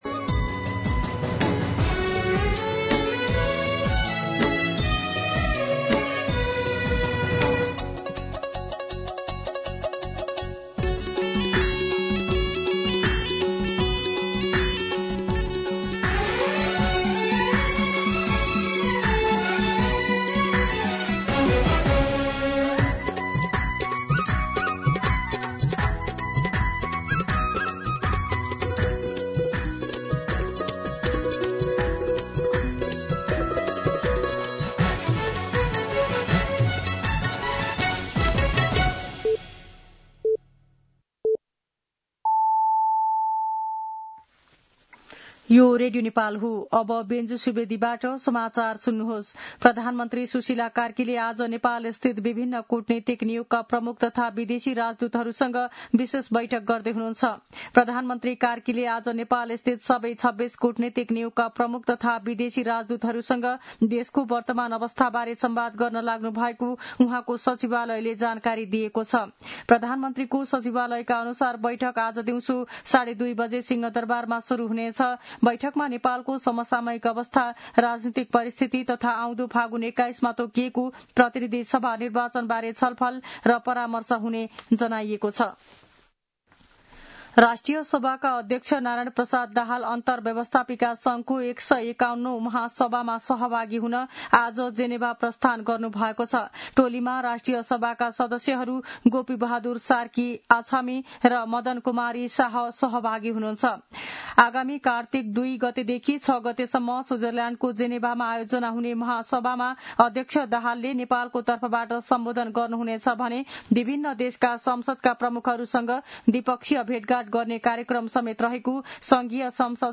मध्यान्ह १२ बजेको नेपाली समाचार : ३१ असोज , २०८२
12-pm-Nepali-News-6.mp3